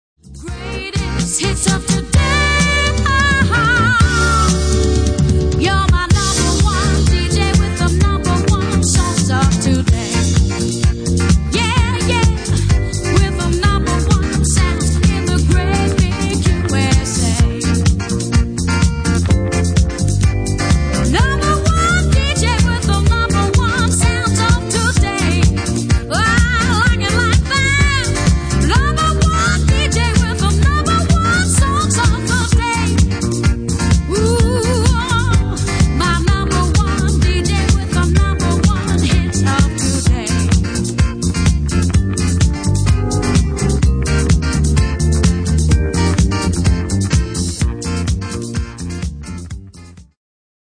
Genere:   Disco Funky